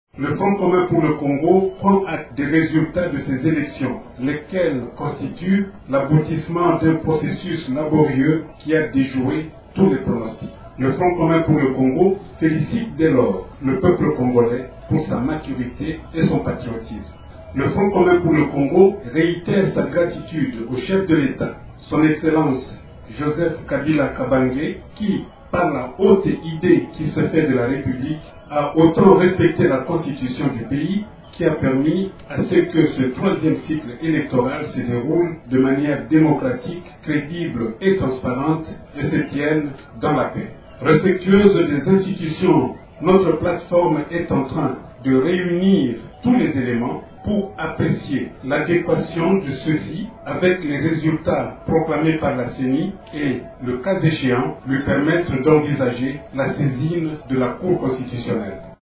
Intervenant sur Radio Okapi, Kikaya Bin Karubi, a fait cependant remarquer que, « respectueuse des institutions, (sa) plate-forme est en train de réunir tous les éléments pour apprécier l’adéquation de ceux-ci et les résultats proclamer par la CENI et, le cas échéant, lui permettre d’envisager la saisine de la Cour constitutionnelle ».